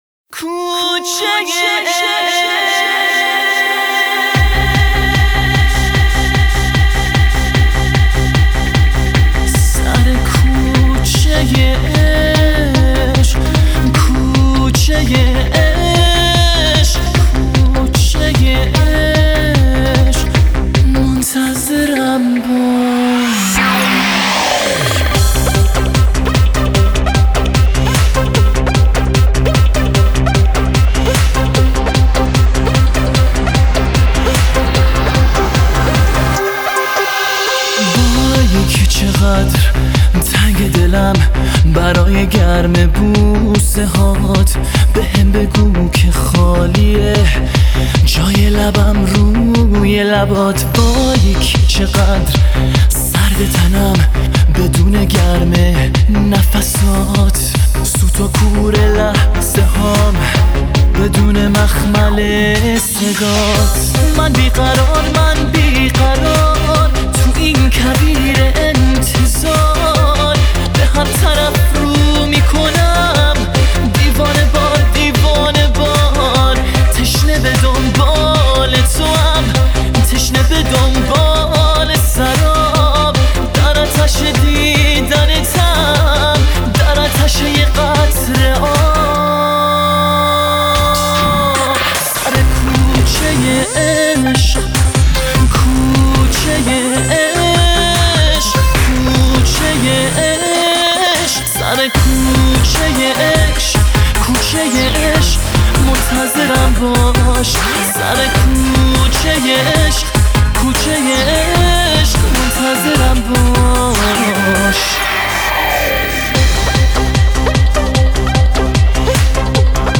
Dubstep Remix